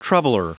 Prononciation du mot troubler en anglais (fichier audio)
Prononciation du mot : troubler